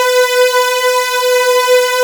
strings.wav